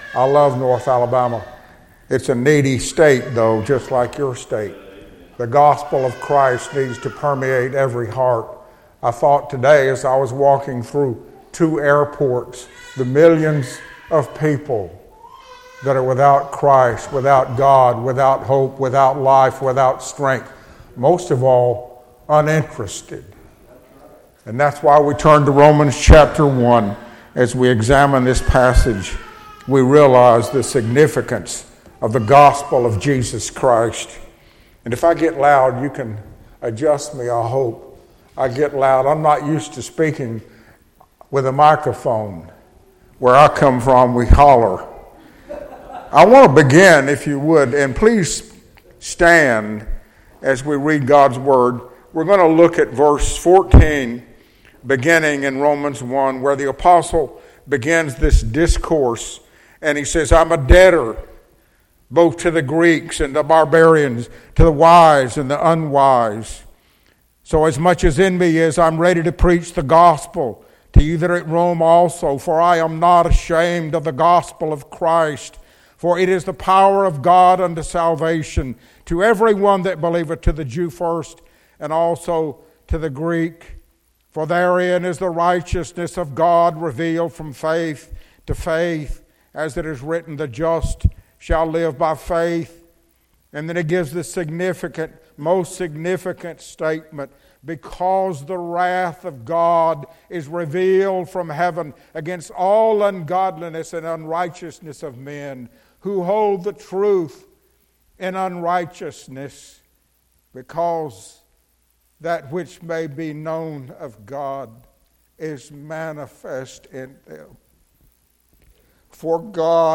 Session: Evening Session